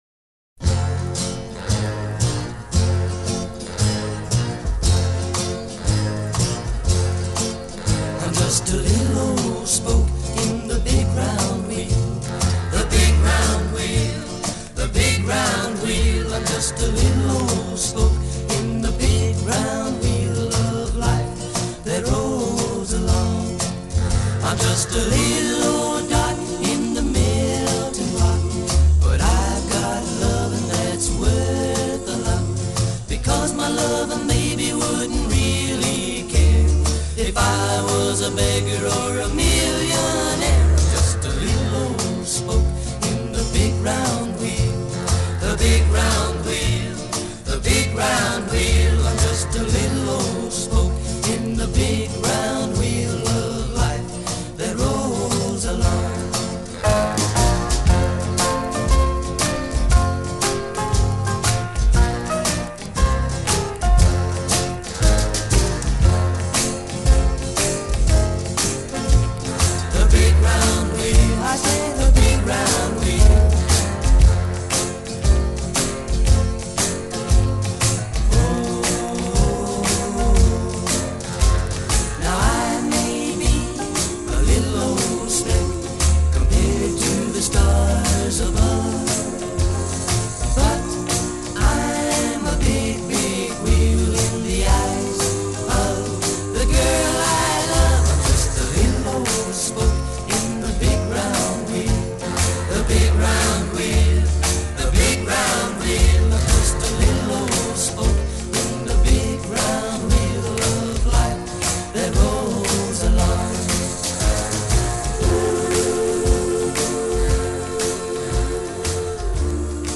Guitar,vocals
bass
drums